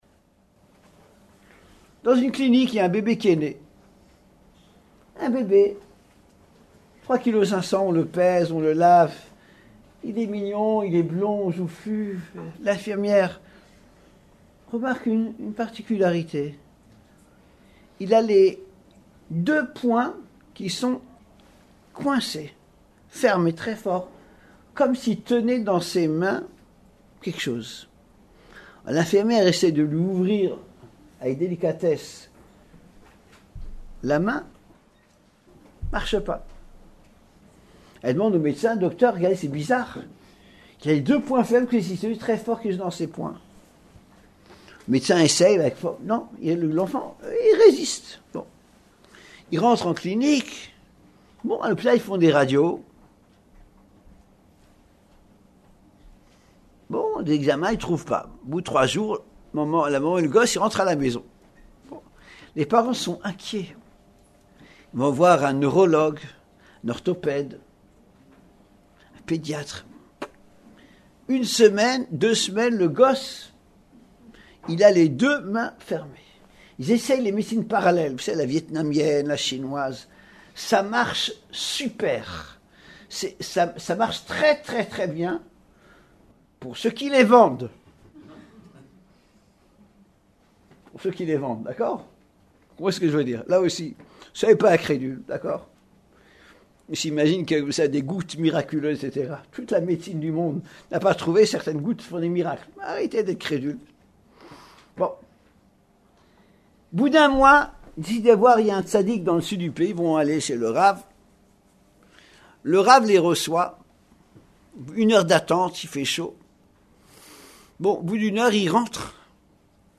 Il y a animé plusieurs ateliers de Shiourim, tant spécifiquement pour Messieurs d’une part que pour Dames, d’autre part.